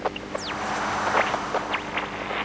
nerfs_psynoise13.ogg